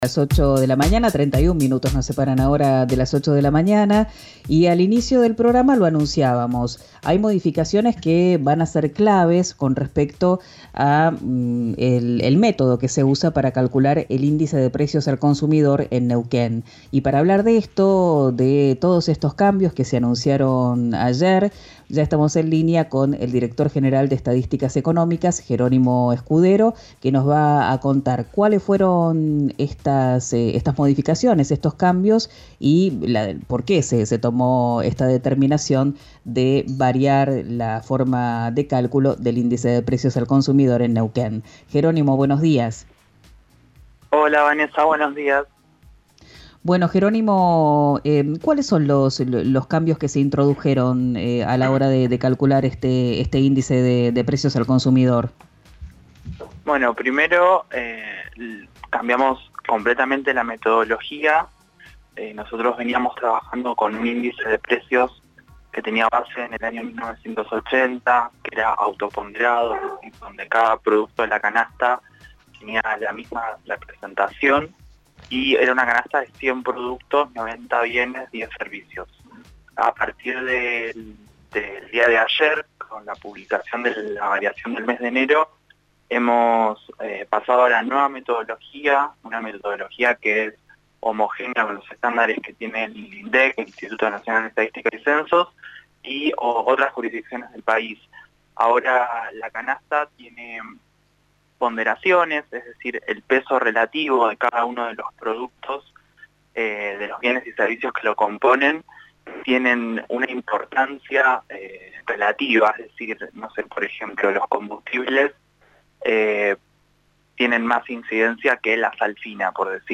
En diálogo con «Quién dijo verano», por RÍO NEGRO RADIO